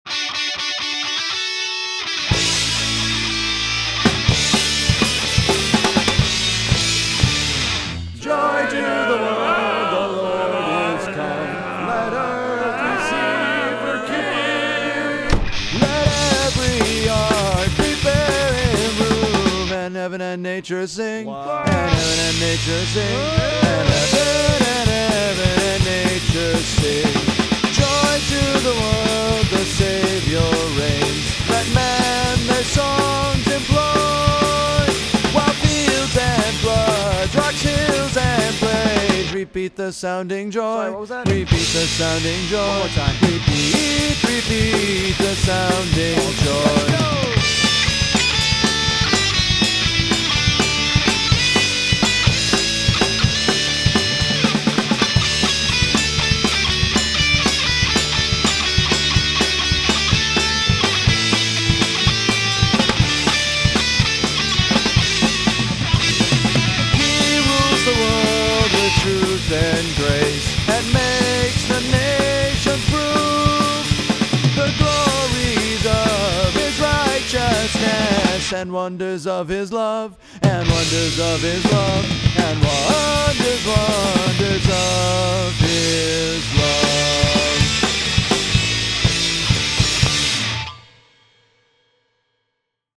But, since we can’t just ignore traditions, all of mine are in place – the “way too fast” song, the harmonized guitar lead, “holy crap”, and even that awesome crashing sound.
Recorded and mixed at The House, Rochester, NY, Nov-Dec 2013.
Additional vocals/yelling